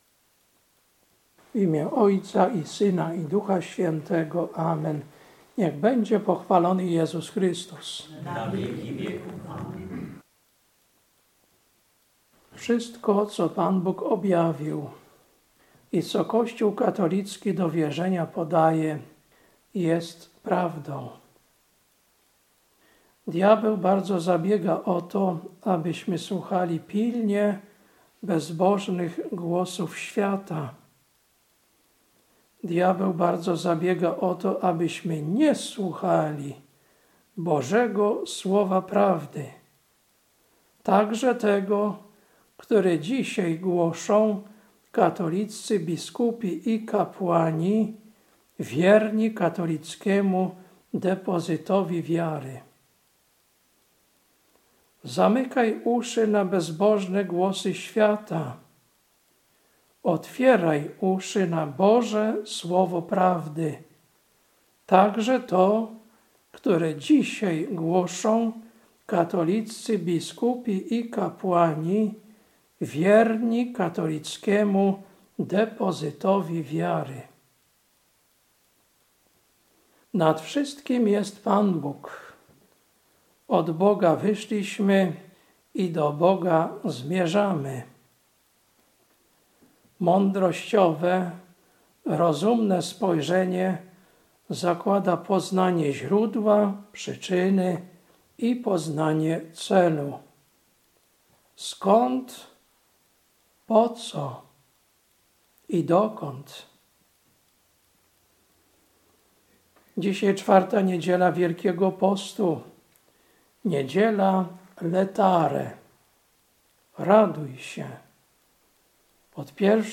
Kazanie na IV Niedzielę Wielkiego Postu – Laetare, 15.03.2026 Lekcja: Ga 4, 22-31 Ewangelia: J 6, 1-15